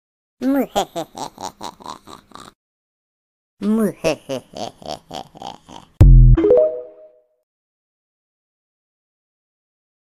evil smile by a cat sound effects free download